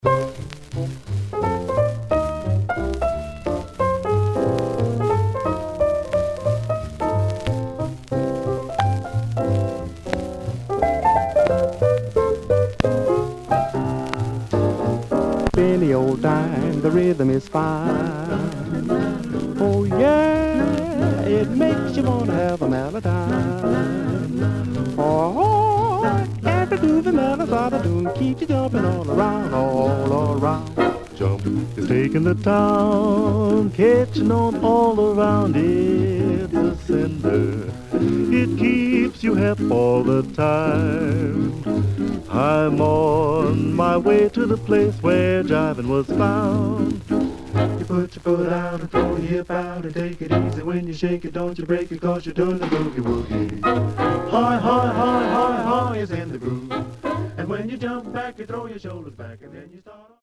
Some surface noise/wear Stereo/mono Mono
Male Black Groups